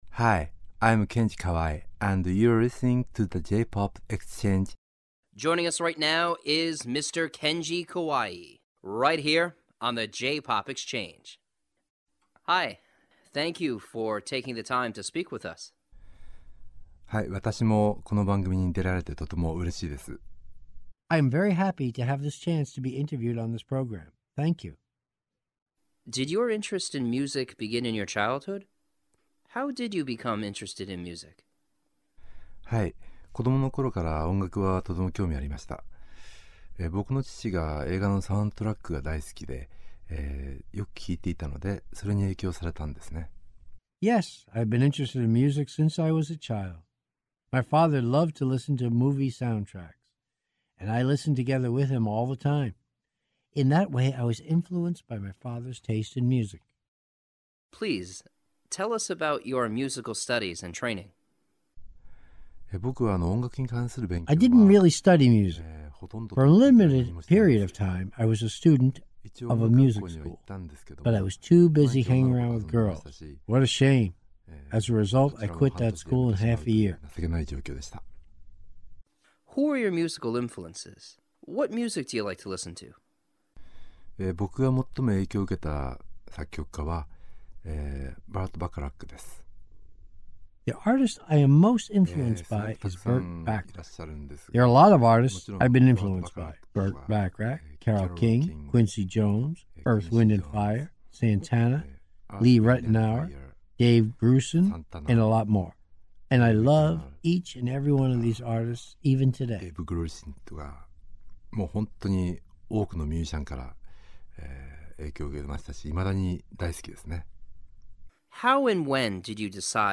The_JPop_Exchange_Kenji_Kawai_Exclusive_Interview.mp3